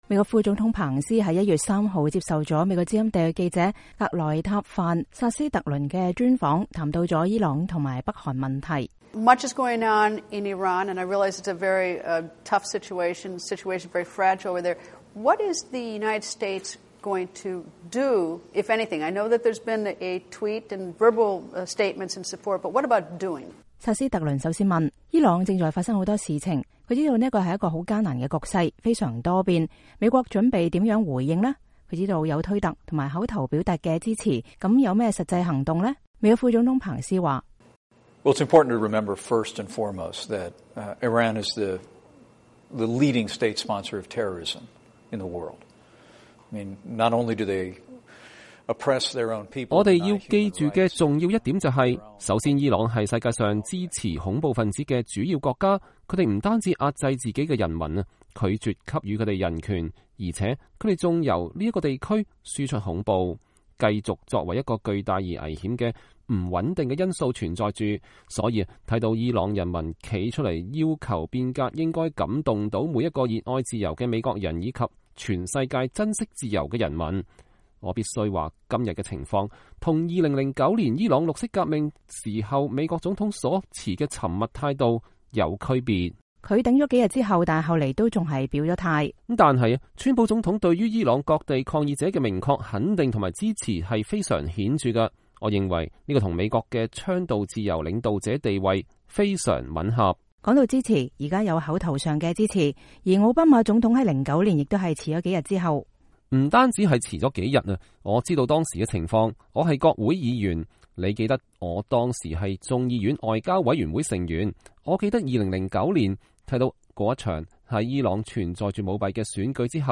VOA專訪：彭斯副總統談伊朗與北韓問題